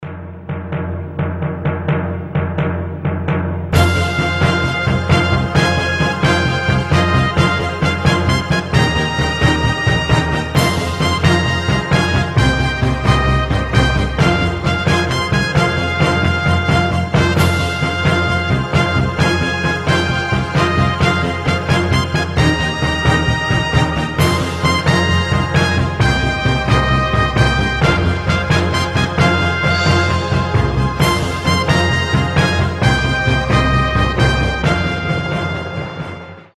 без слов
оркестр
эпичные
барабаны
из телешоу
боевые
Знаменитая мелодия из приключенческого шоу